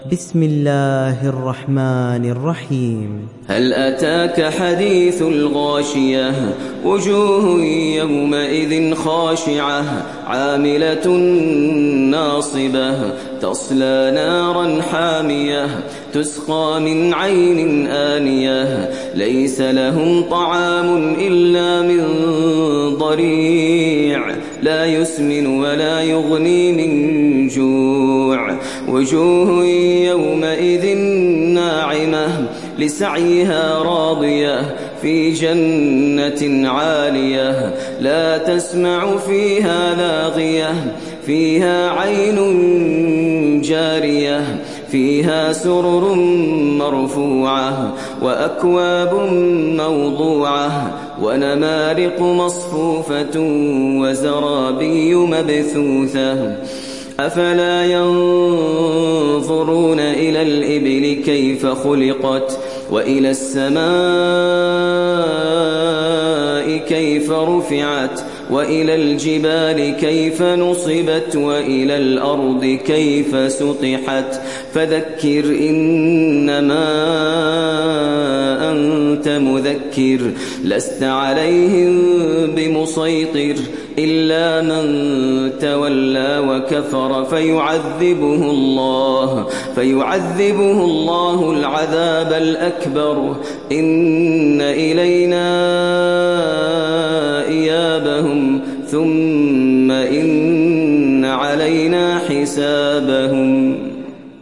Surat Al Ghashiyah Download mp3 Maher Al Muaiqly Riwayat Hafs dari Asim, Download Quran dan mendengarkan mp3 tautan langsung penuh